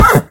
sounds / mob / horse / hit2.mp3